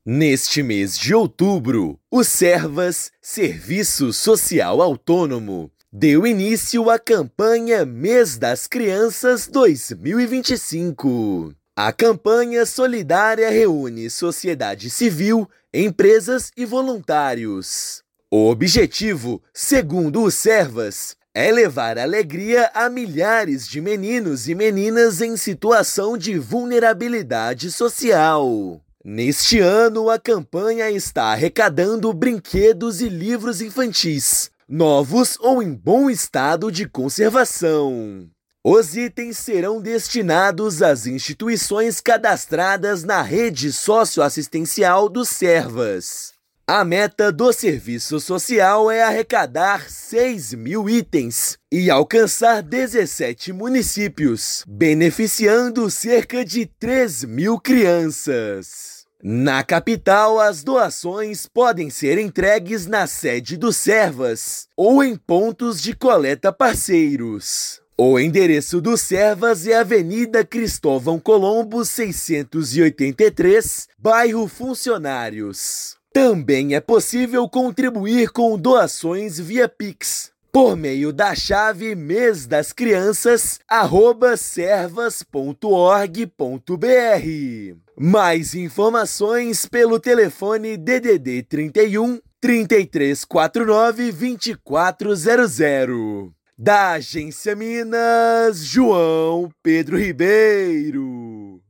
Em 2025, a meta é arrecadar 6 mil itens, alcançar 17 municípios e beneficiar 3 mil crianças; doações também podem ser feitas via Pix. Ouça matéria de rádio.